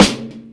Snares
Live_Snro (2).wav